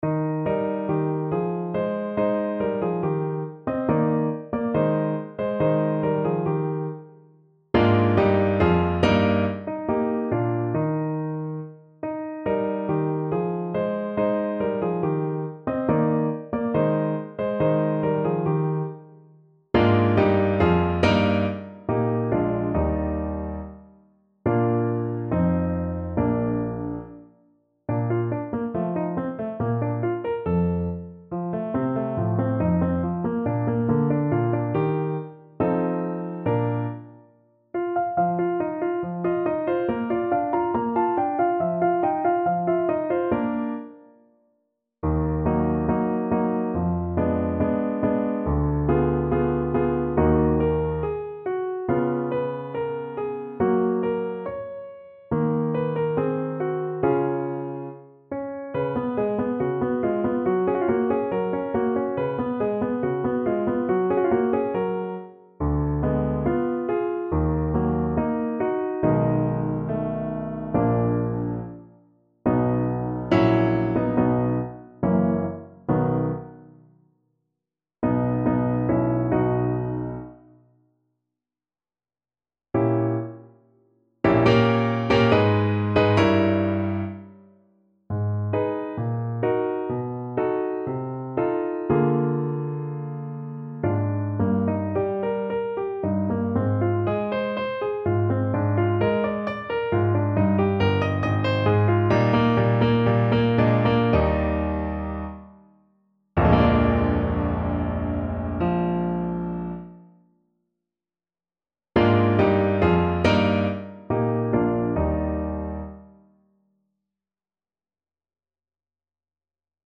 Play (or use space bar on your keyboard) Pause Music Playalong - Piano Accompaniment Playalong Band Accompaniment not yet available reset tempo print settings full screen
Eb major (Sounding Pitch) C major (Alto Saxophone in Eb) (View more Eb major Music for Saxophone )
2/4 (View more 2/4 Music)
= 70 Allegretto
Classical (View more Classical Saxophone Music)